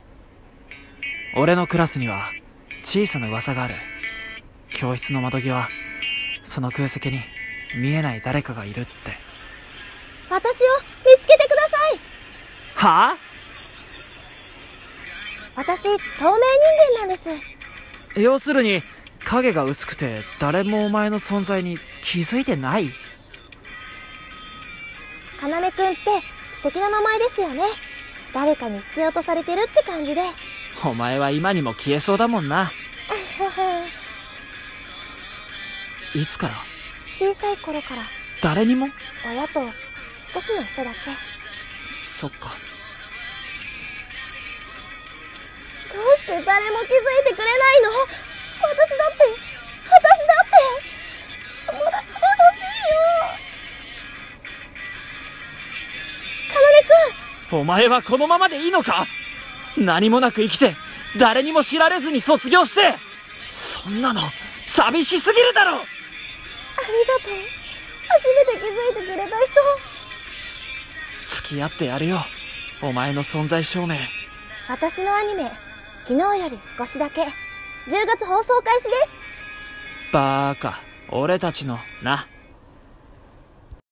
【アニメCM風声劇台本】『昨日より、少しだけ。』